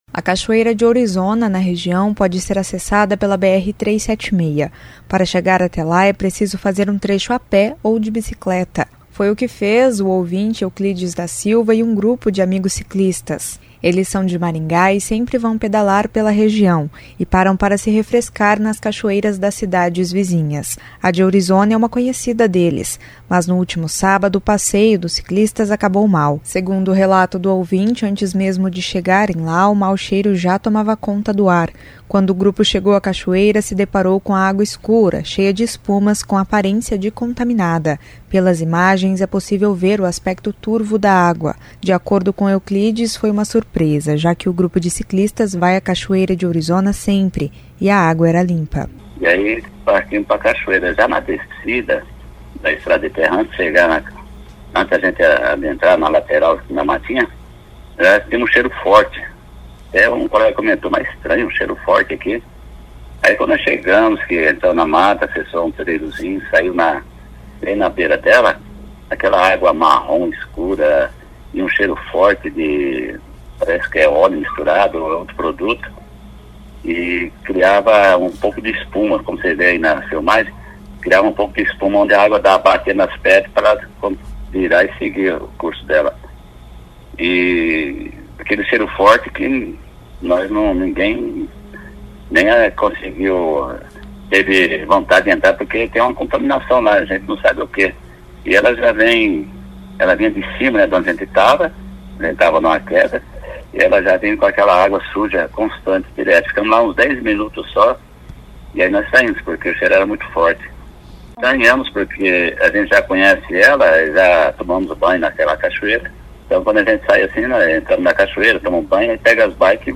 O prefeito de Ourizona, Rodrigo Amado, disse que a água foi coletada pela vigilância sanitária para ser analisada.